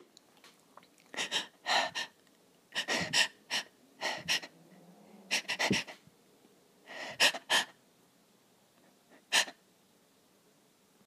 breath.mp3